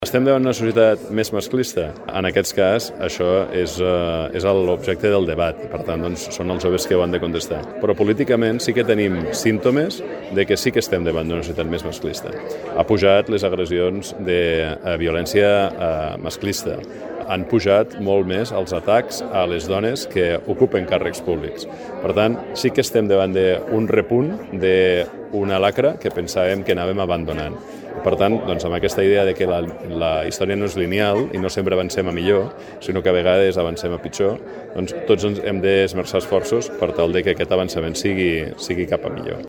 En l’obertura, el síndic general, Carles Ensenyat, ha defensat l’oportunitat de la temàtica i ha advertit d’un repunt del masclisme a la societat.